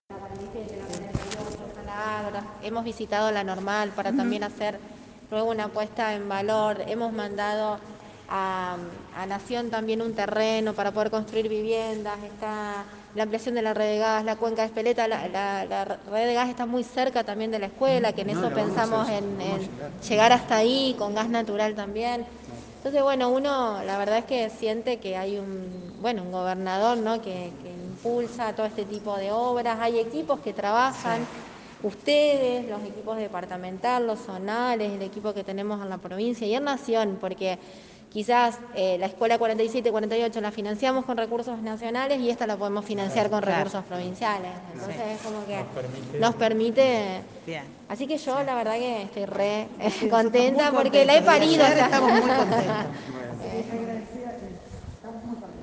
Móvil LT39 en rueda de prensa